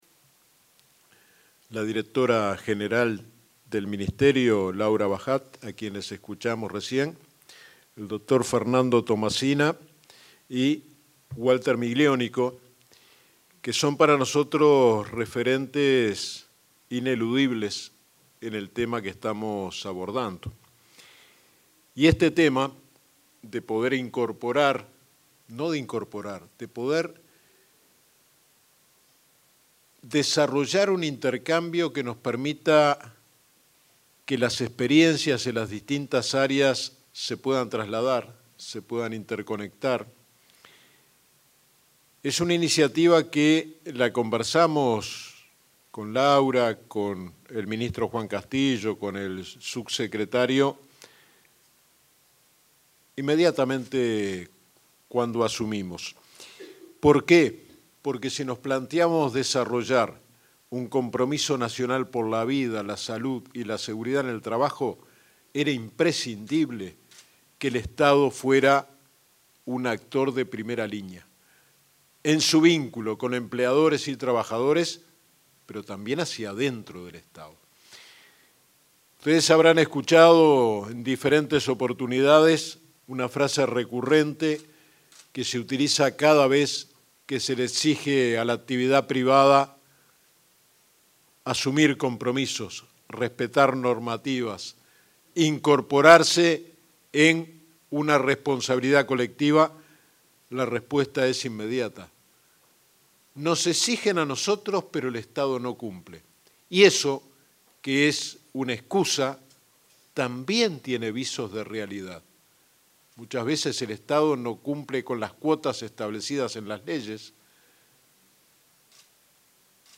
Palabras del inspector general del Trabajo y de la Seguridad Social, Luis Puig
Palabras del inspector general del Trabajo y de la Seguridad Social, Luis Puig 21/08/2025 Compartir Facebook X Copiar enlace WhatsApp LinkedIn El inspector general del Trabajo y de la Seguridad Social, Luis Puig, se expresó durante la presentación de una jornada de formación sobre seguridad y salud ocupacional, para jerarcas de organismos públicos.